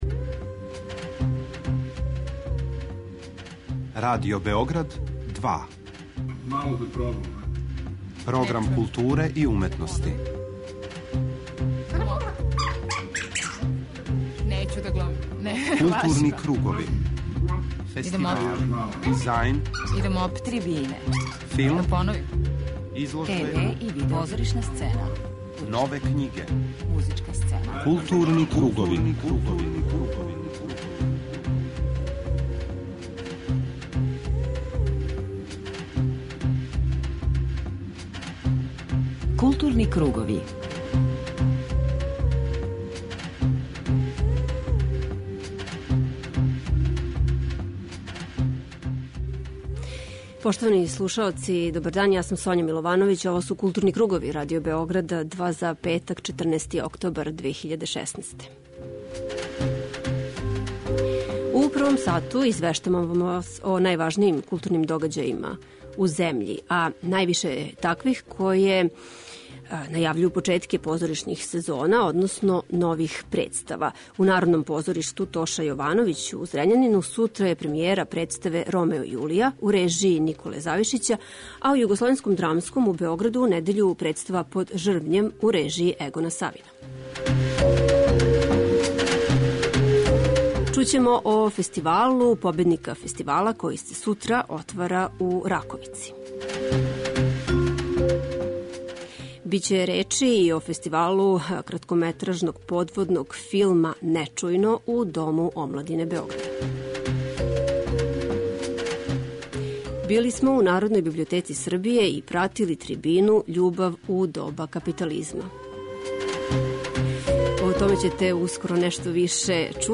Осим што су песме овде и уједно и приче, из личног албума, и необична композиција књиге "Прасвршетак" носи одређена значења, о чему, између осталог, разговарамо са нашим гостом.